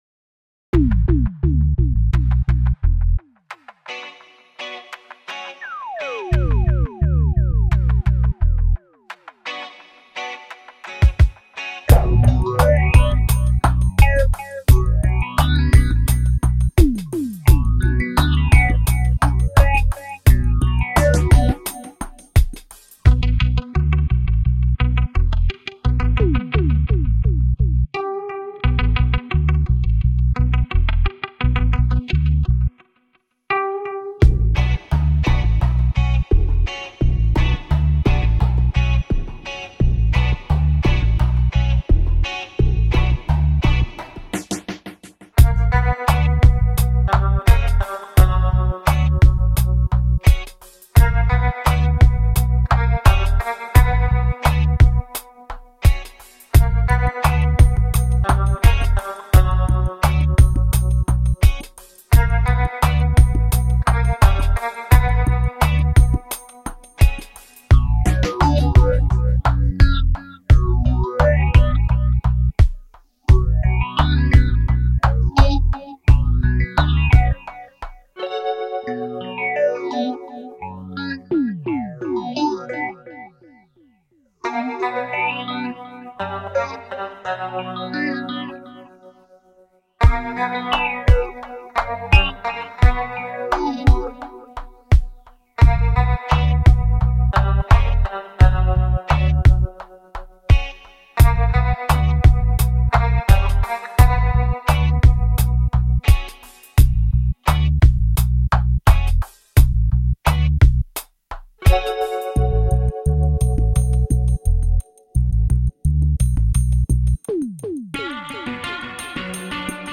Super fine dub .
Tagged as: Electronica, World, Reggae, Dub